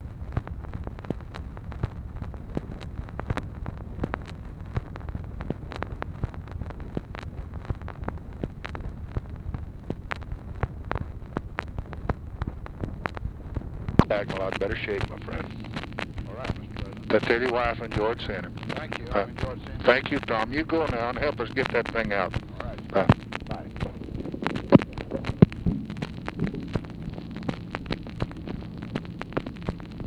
Conversation with TOM ABERNETHY, March 9, 1964
Secret White House Tapes